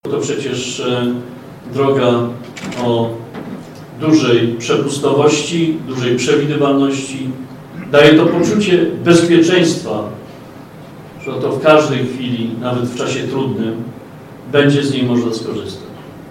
Minister dodaje, że ta droga jest niezmiernie ważna nie tylko ze społecznego punktu widzenia, ale także gospodarczego i militarnego.